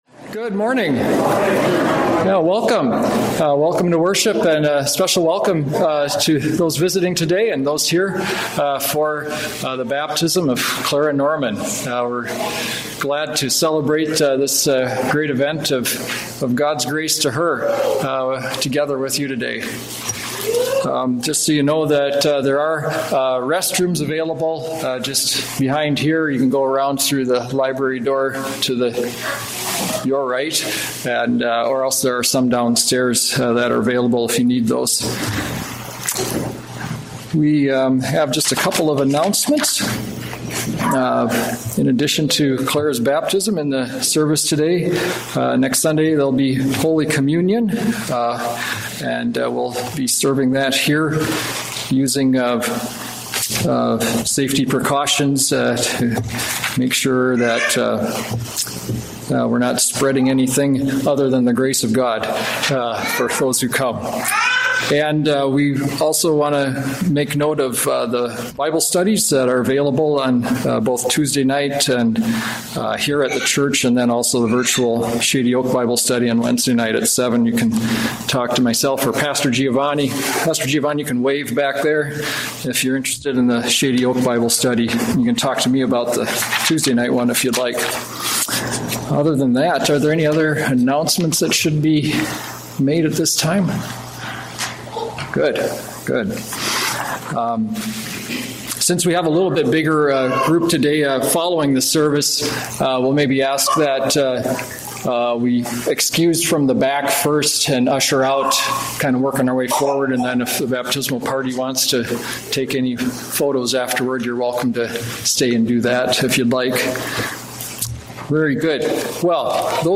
From Series: "Sunday Worship"